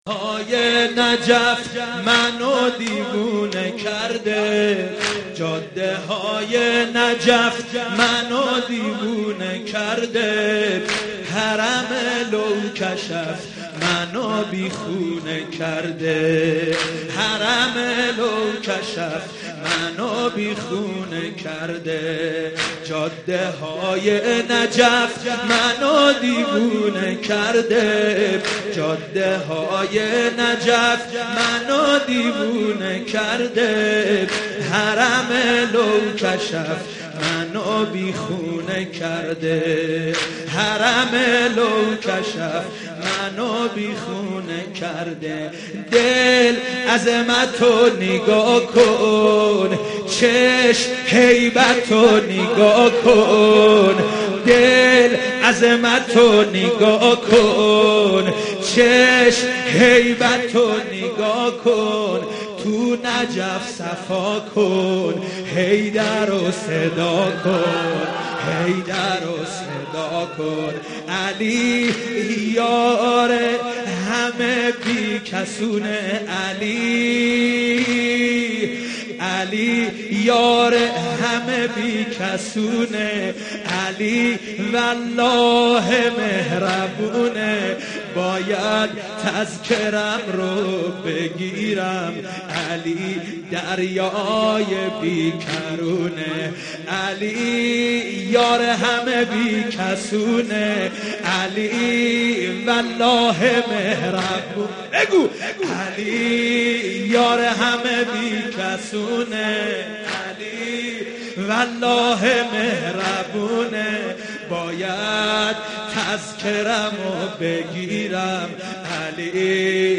رمضان 89 - سینه زنی 2